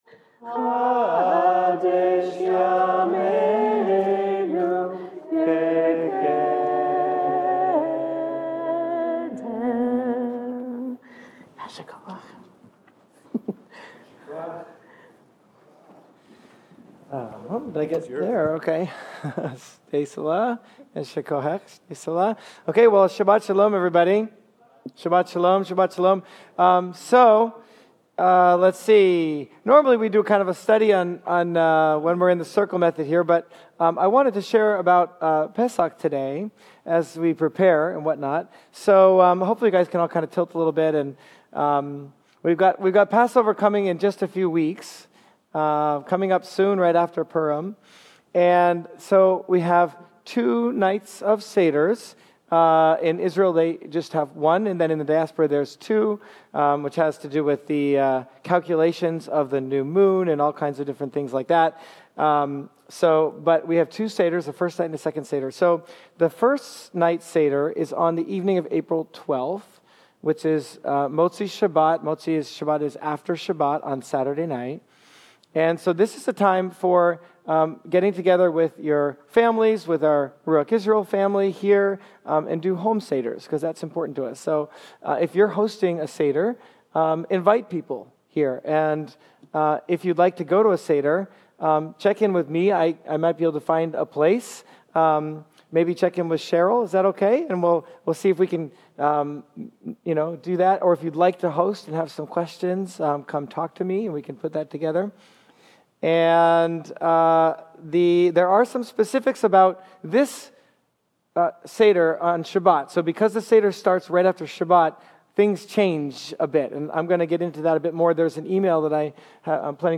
In this sermon, we’ll walk through the rooms of the Temple and explore how we can build a “Mishkan” (tabernacle) in our hearts, creating a space for G-d’s presence to dwell.